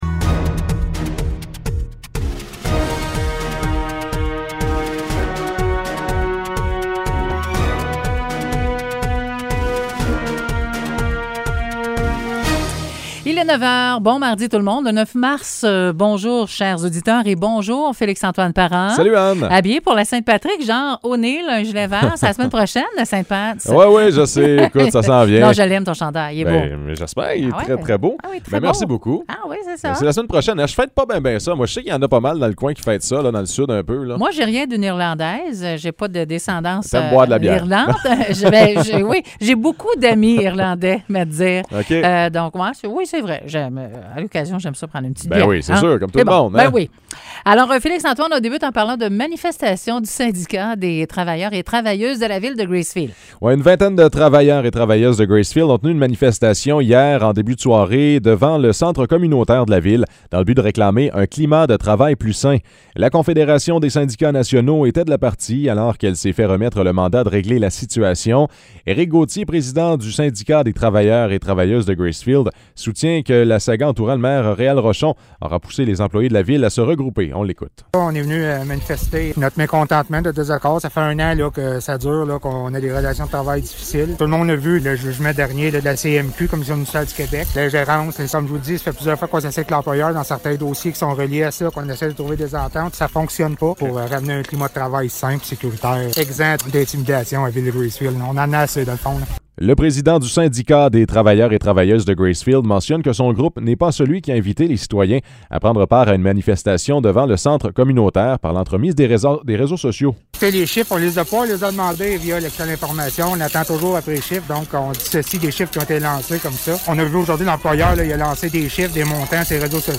Nouvelles locales - 9 mars 2021 - 9 h